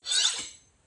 sword_pickup.wav